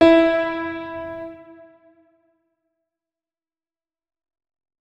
piano
notes-40.ogg